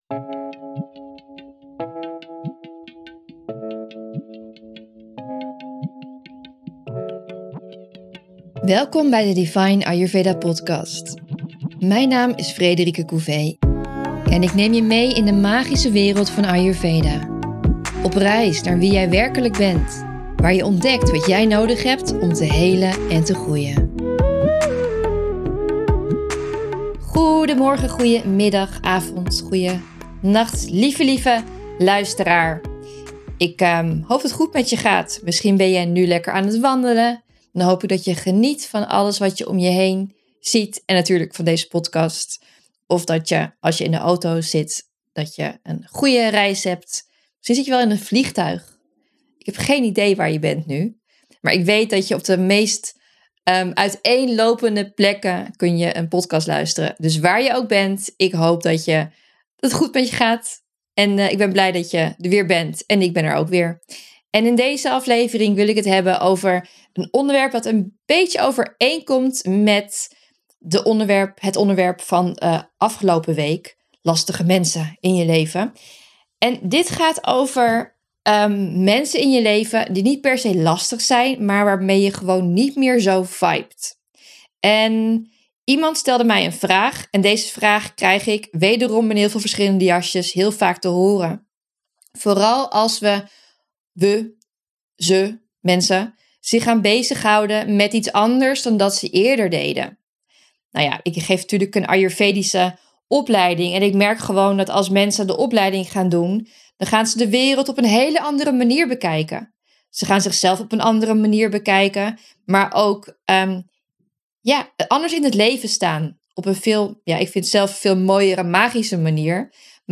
Wederom een aflevering live vanuit India! Dit keer vanuit Rishikesh, gevuld met Shiva energie en de heilige rivier de Ganges…1 februari is de eerste editie van het jaar van de Vedic Church, mijn live event met mooie muziek, samen delen en helen en enorm genieten van het samen zijn in een bijzondere energie.